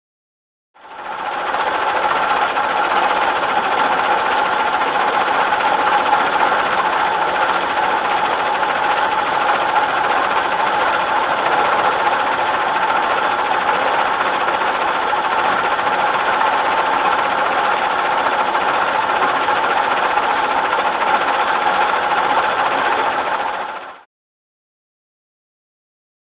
Automobile; Idle; Vintage Dennis Truck Idling.